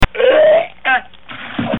Upchuck
Category: Television   Right: Personal